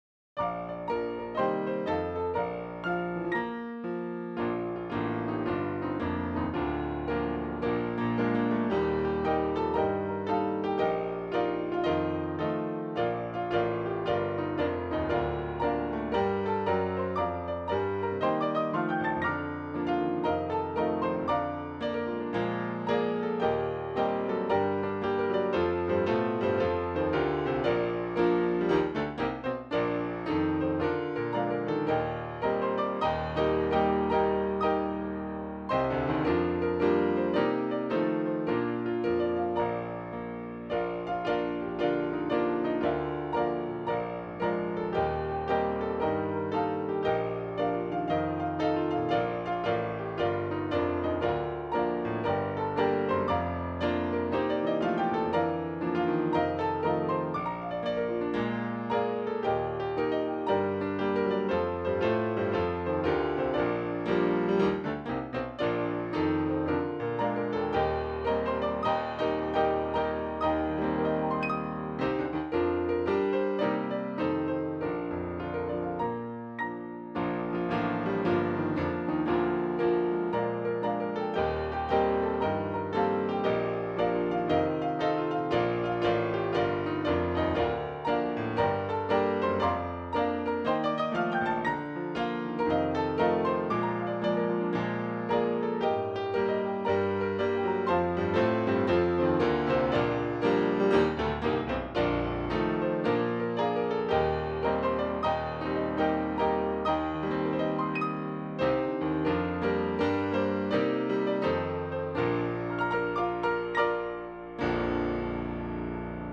Key: B♭